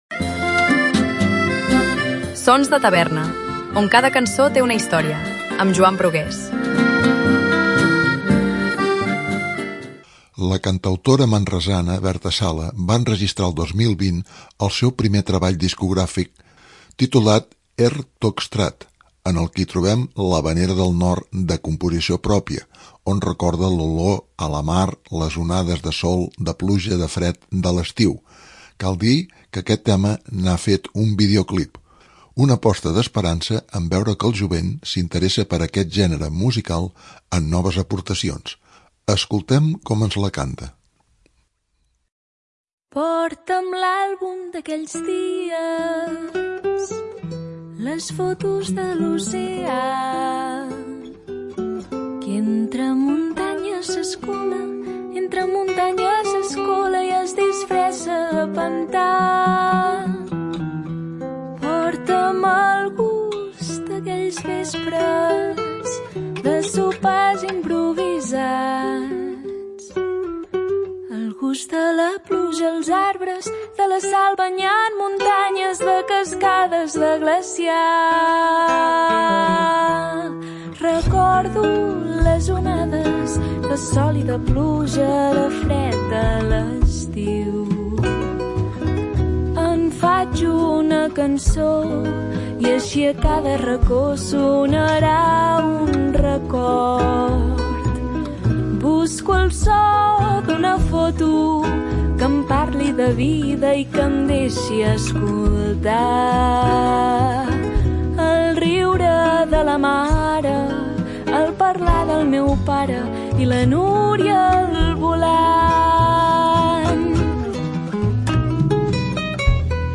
La cantautora manresana